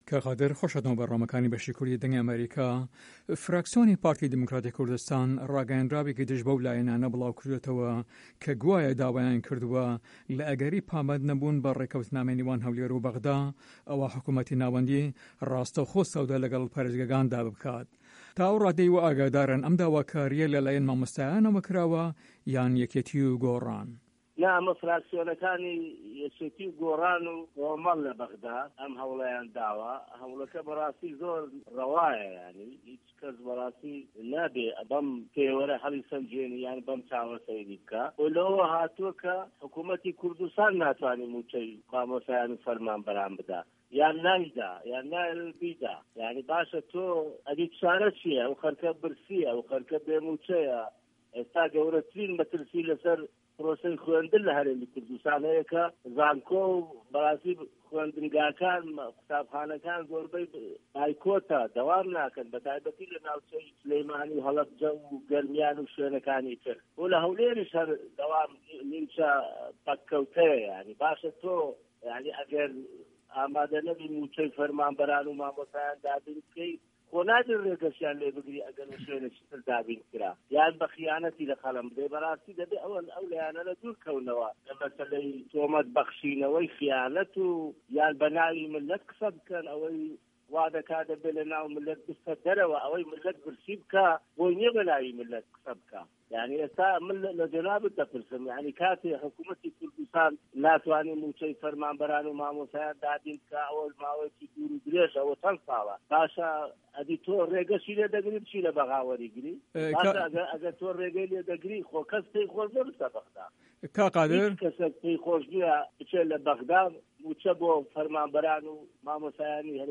Interview with Qadir Aziz